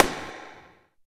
34 SNARE 2-R.wav